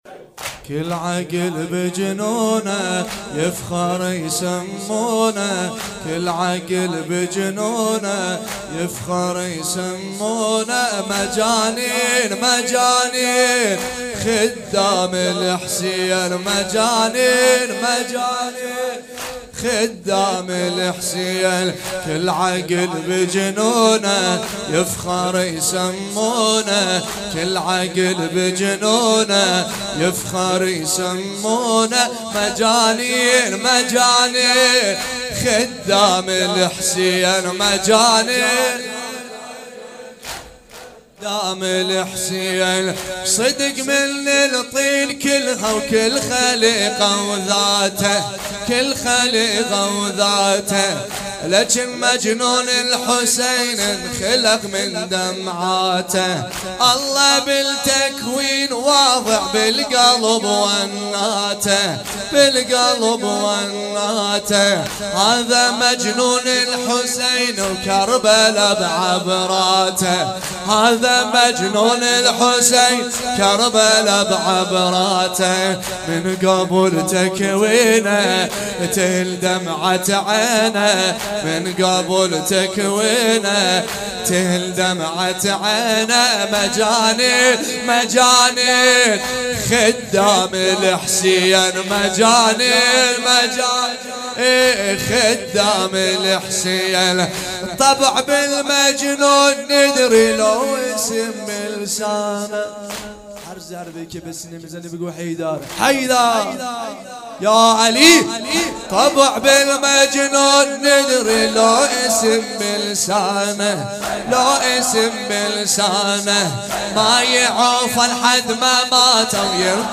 چهاراه شیرودی حسینیه حضرت زینب(س)